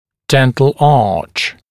[‘dent(ə)l ɑːʧ][‘дэнт(э)л а:ч]зубная дуга, зубной ряд